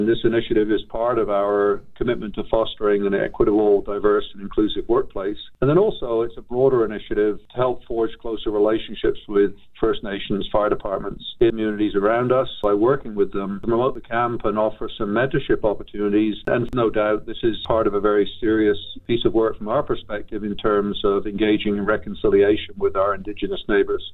Calgary Fire Chief Steve Dongworth says it is important for the City’s fire service to reflect the community it serves.
calgary-fire-chief-clip.mp3